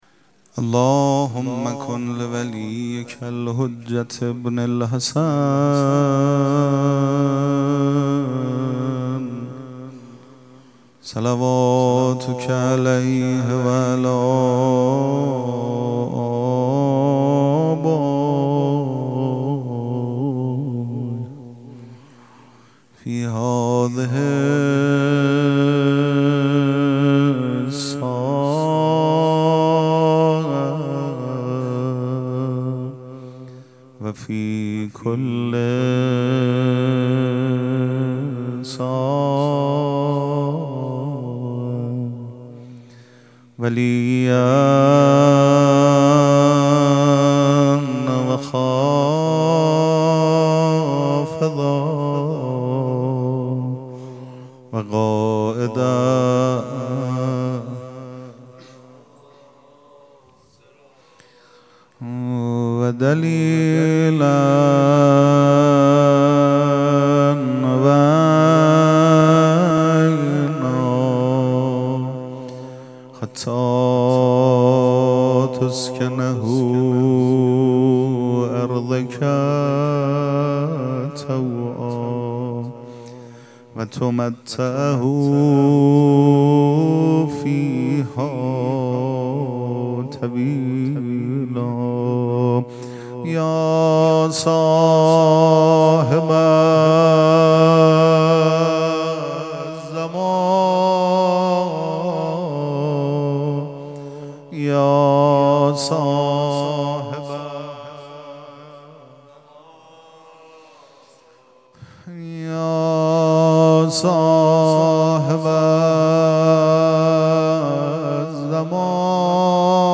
ذکر توسل
مراسم عزاداری شهادت امام صادق علیه‌السّلام